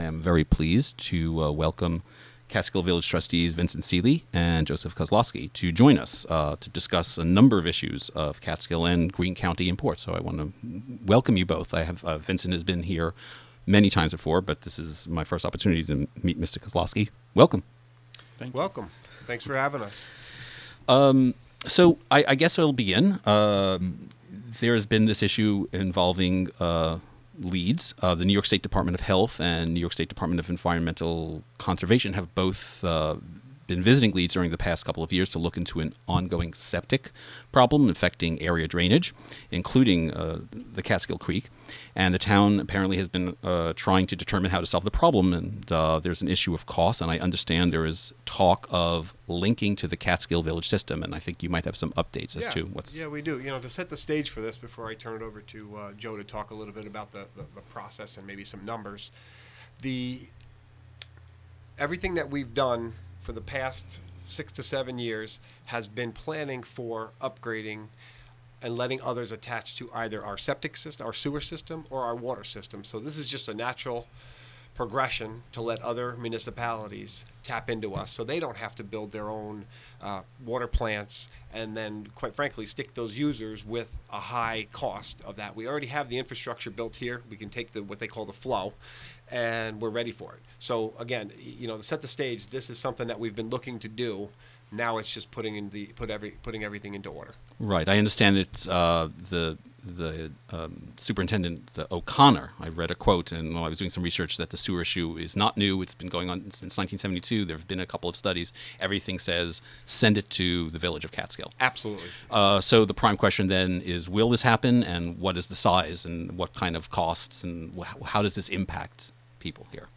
Interview
From "WGXC Afternoon Show" from Catskill Community Center.